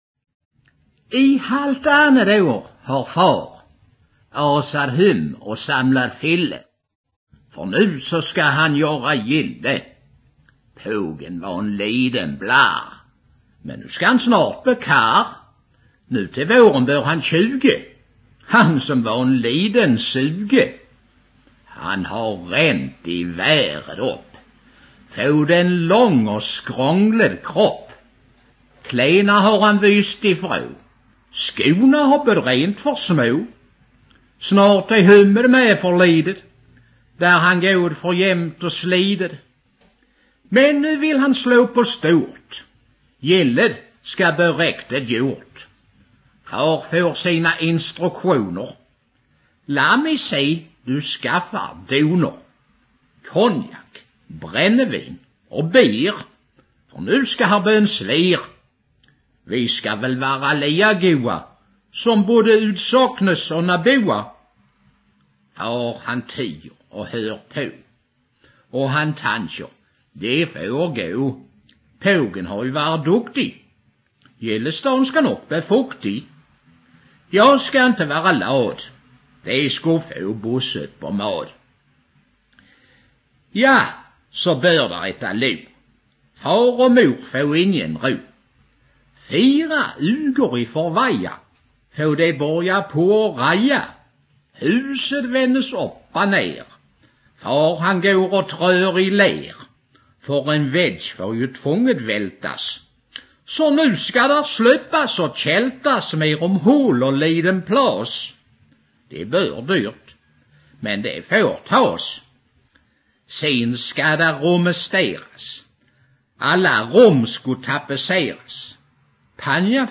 en dikt på Österlendialekt